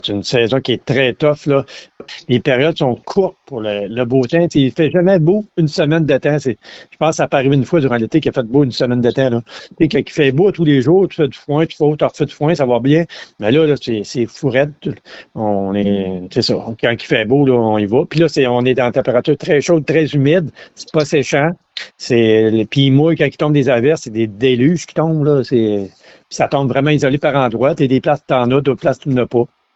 Étant un agriculteur, le préfet de la MRC de Bécancour, Mario Lyonnais, est revenu sur les dernières semaines.